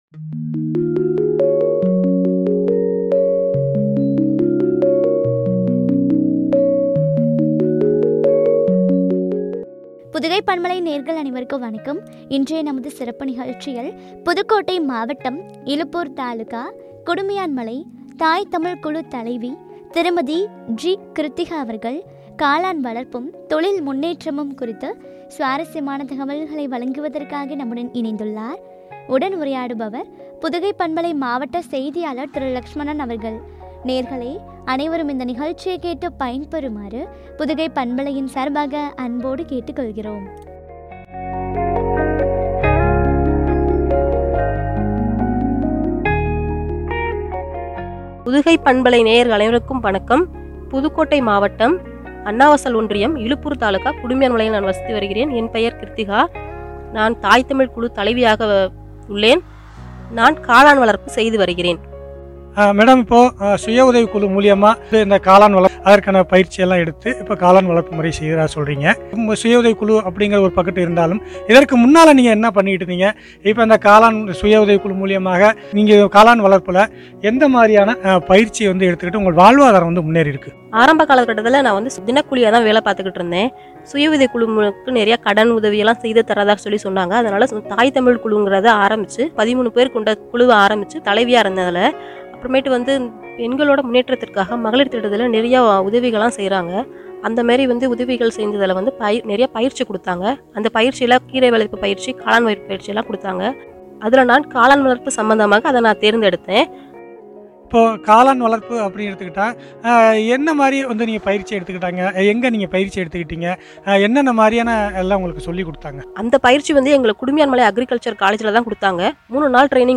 காளான் வளர்ப்பும், தொழில் முன்னேற்றமும் குறித்து வழங்கிய உரையாடல்.